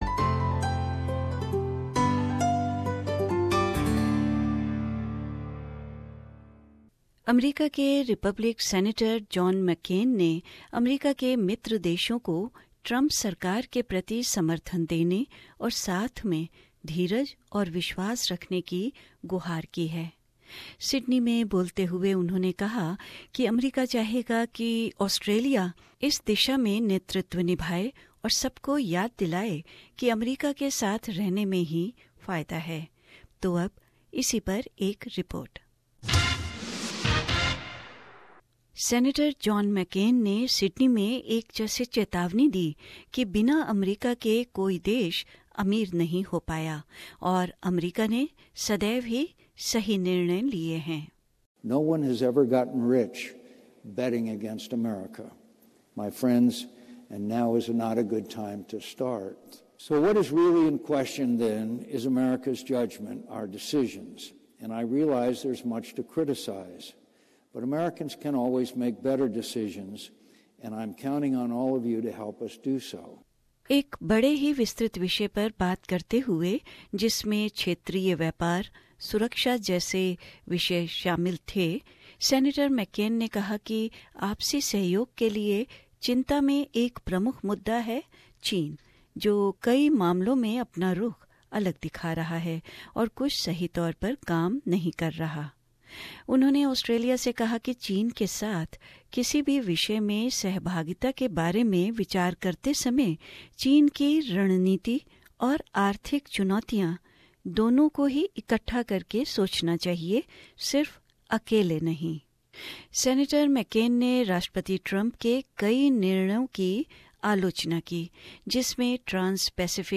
John McCain delivers a speech at the invitation of the United States Studies Centre in Sydney Source: AAP